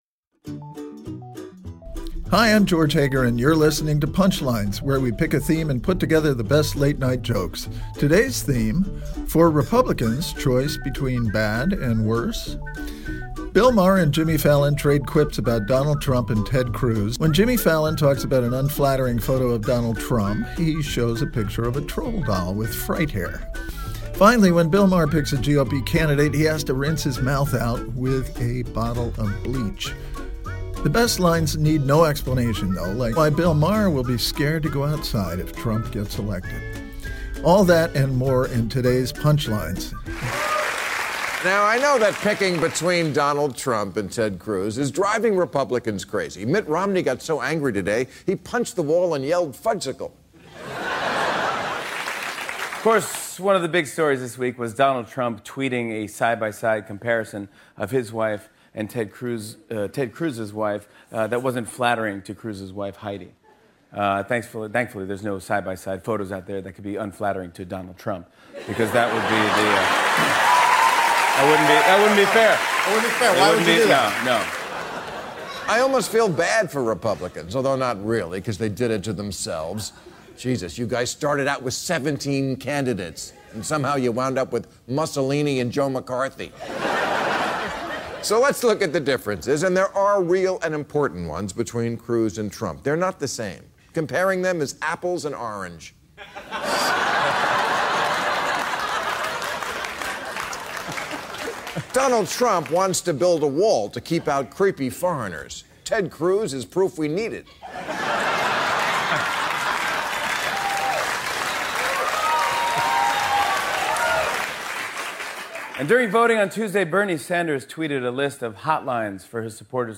Late-night comics on the latest political news, including the ill-fated decision between Trump and Cruz.